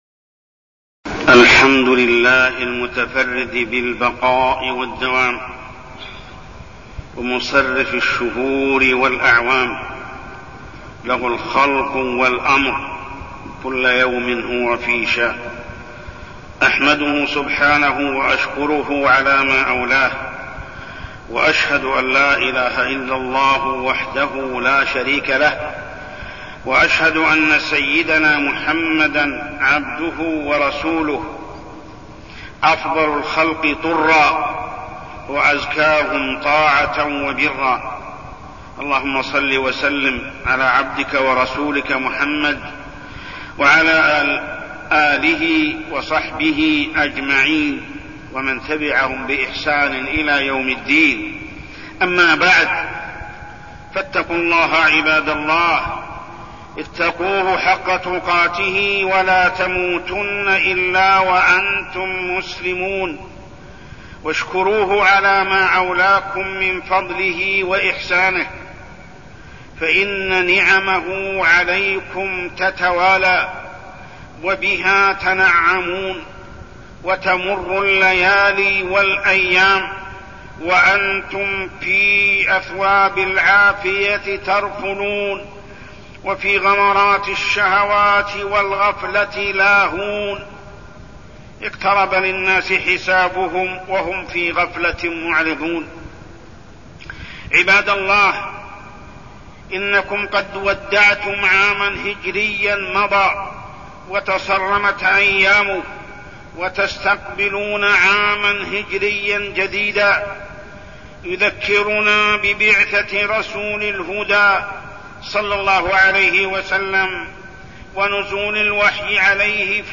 تاريخ النشر ١٥ محرم ١٤١٥ هـ المكان: المسجد الحرام الشيخ: محمد بن عبد الله السبيل محمد بن عبد الله السبيل صبر النبي صلى الله عليه وسلم